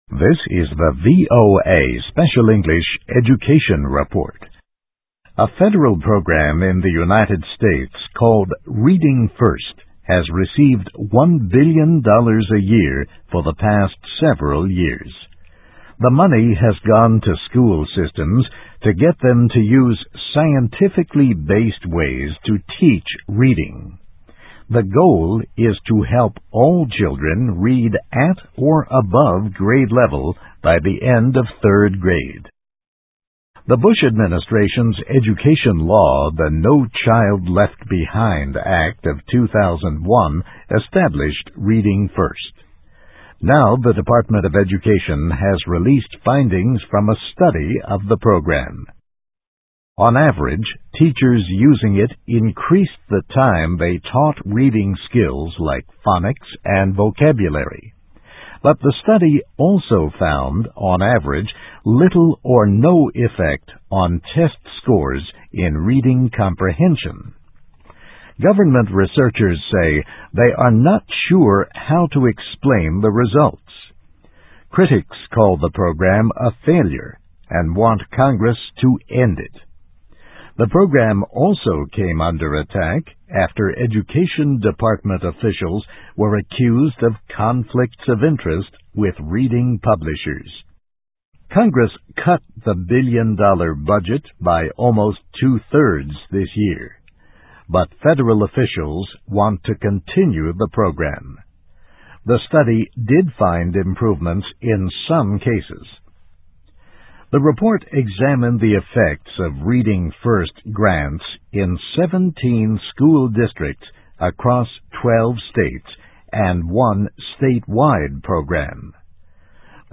Study Raises Questions About Reading Program in US (VOA Special English 2008-05-14)